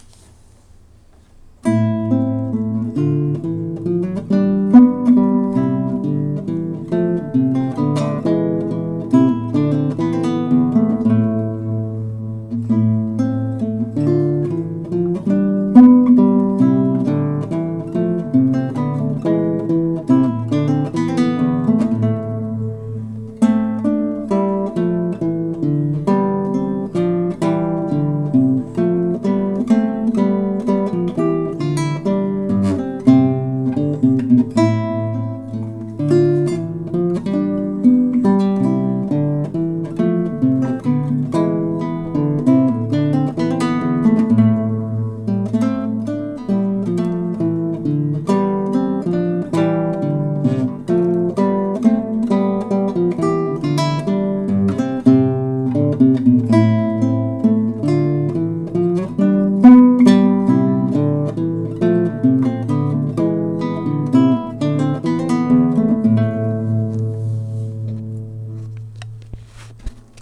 Live Classical Guitar performances